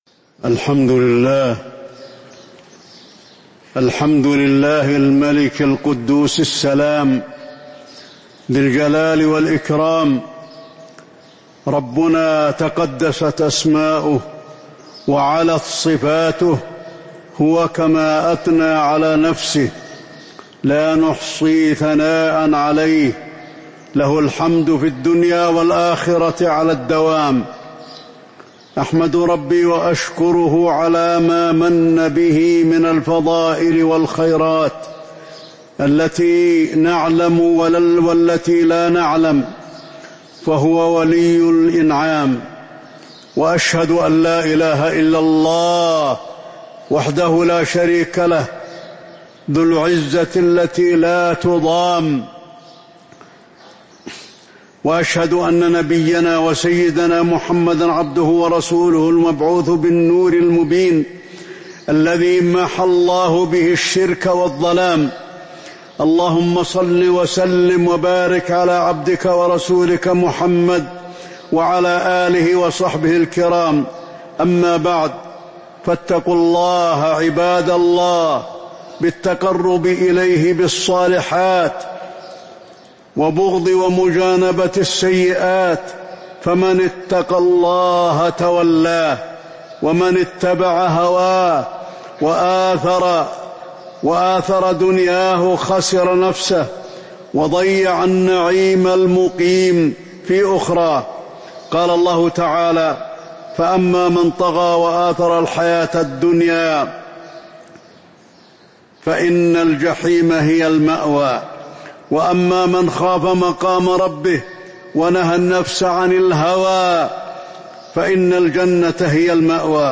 تاريخ النشر ٢١ ربيع الأول ١٤٤٥ هـ المكان: المسجد النبوي الشيخ: فضيلة الشيخ د. علي بن عبدالرحمن الحذيفي فضيلة الشيخ د. علي بن عبدالرحمن الحذيفي ذكر الله تعالى